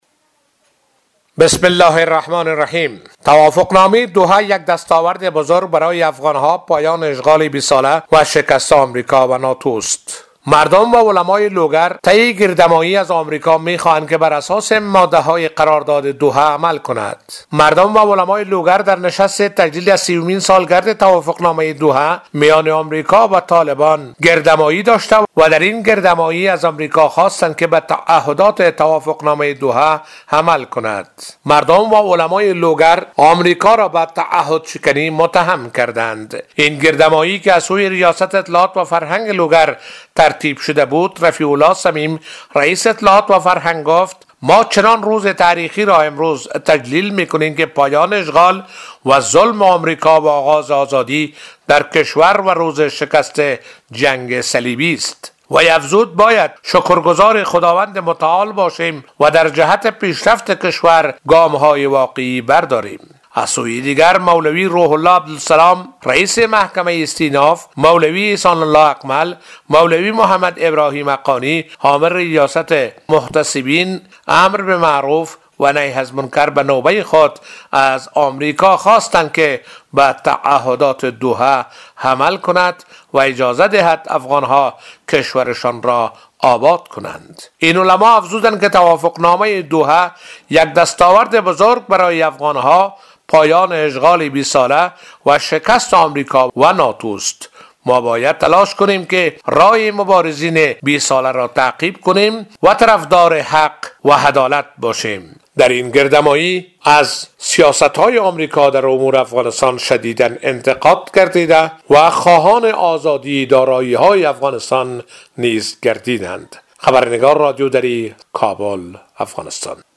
علما و مردم لوگر یک گردهمایی را به مناسبت سومین سالگرد توافقنامه دوحه برگزار کردند.